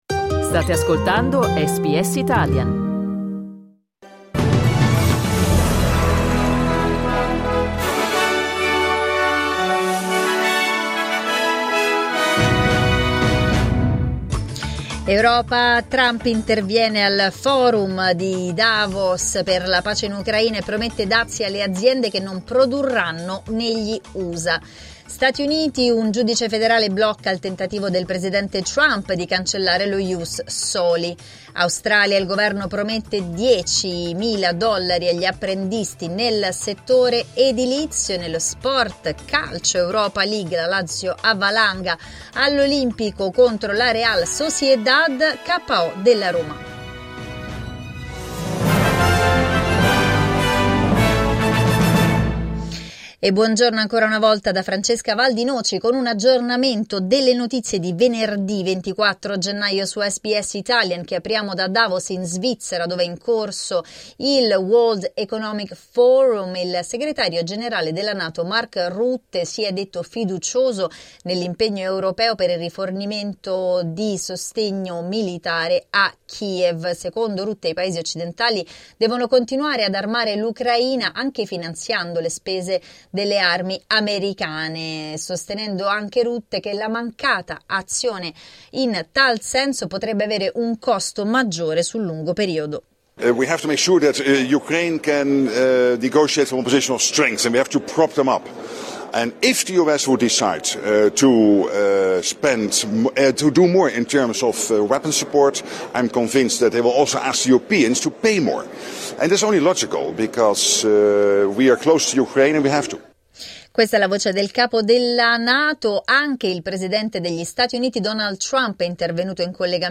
Giornale radio venerdì 24 gennaio 2025
Il notiziario di SBS in italiano.